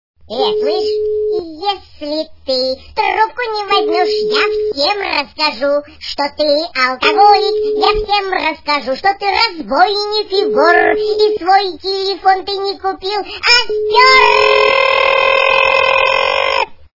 » Звуки » Смешные » Эй, слышишь? - Если ты трубку не возьмешь....
При прослушивании Эй, слышишь? - Если ты трубку не возьмешь.... качество понижено и присутствуют гудки.